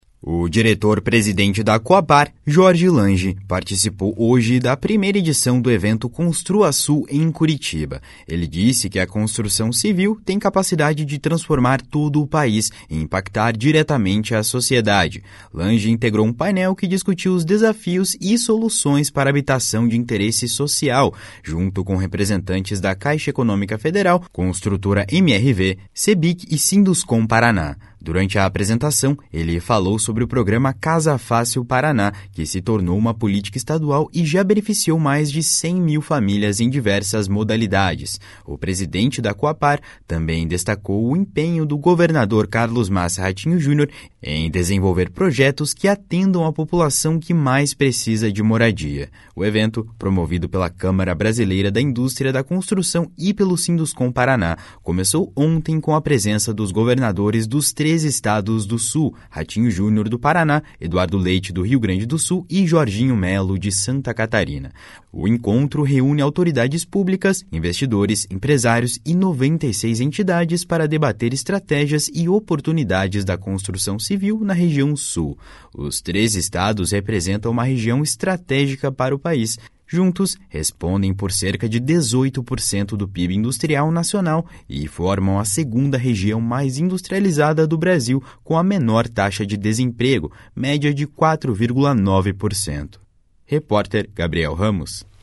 O diretor-presidente da Cohapar, Jorge Lange, participou hoje da primeira edição do evento "Construa Sul", em Curitiba. Ele disse que a construção civil tem capacidade de transformar todo o País e impactar diretamente a sociedade.